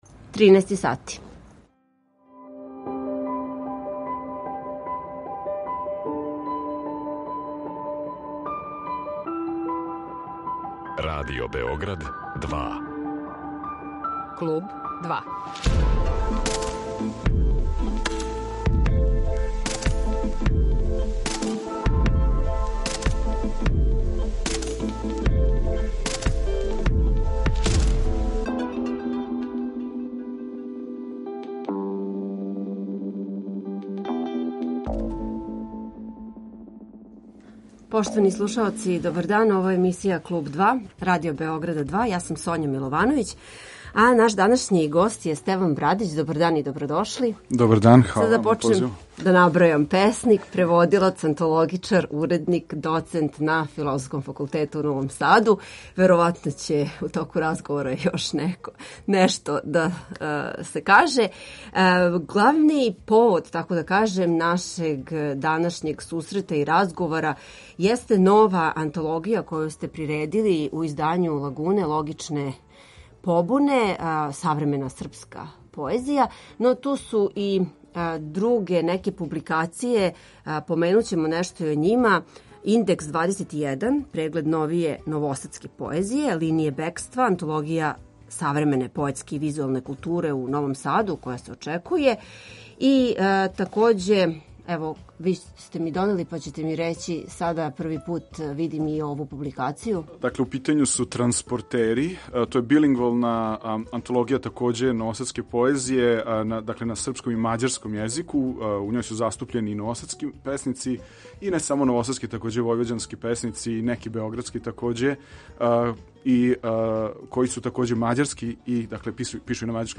О поменутим изборима, и посебно критеријума састављања "Логичних побуна", у које је уврштено четрнаест стваралаца рођених од 1980. до друге половине 1990, разговарамо данас у Клубу 2.